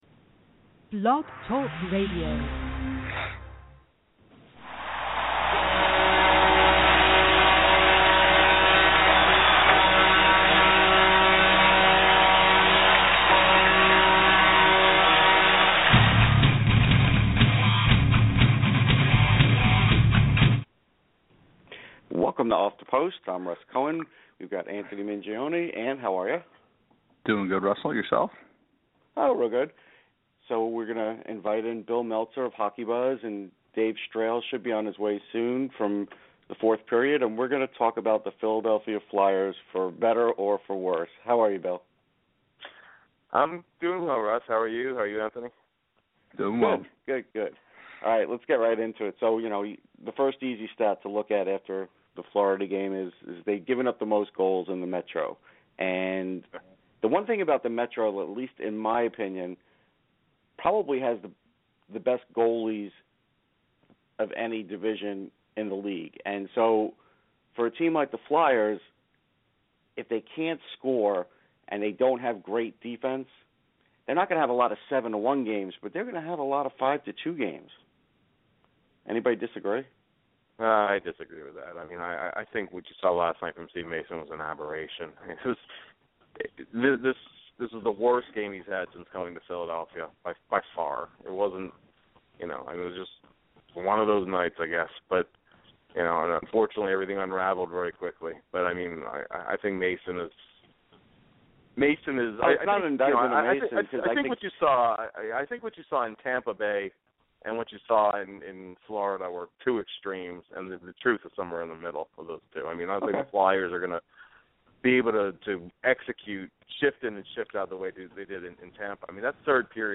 Travis Sanheim is this week's interview.